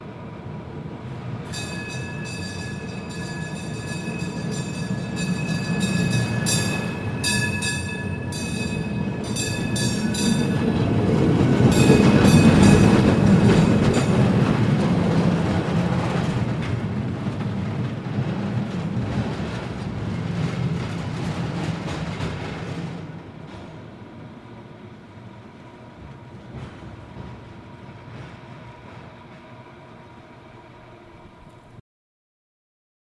Cable Car, San Francisco, By With Bell At Head, Long Out